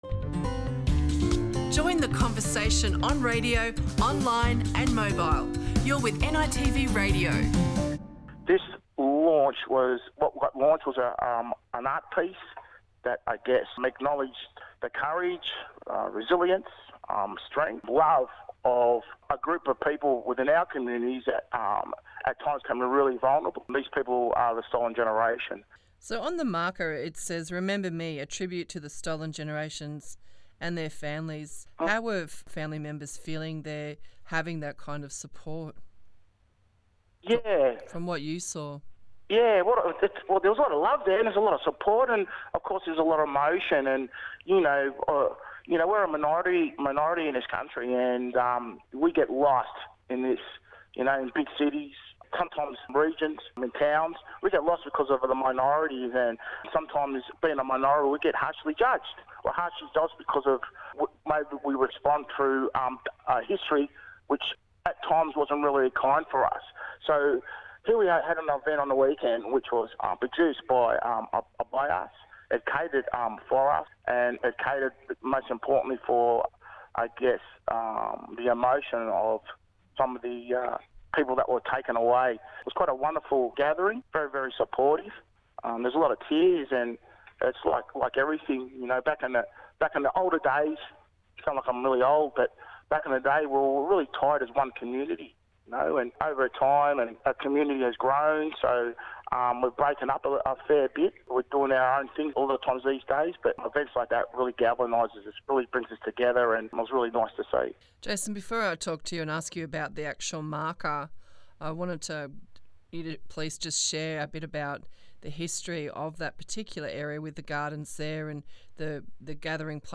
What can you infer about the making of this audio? People gathered in Atherton Gardens in Fitzroy on Saturday to attend the launch of the public artwork 'Remember Me' by Reko Rennie.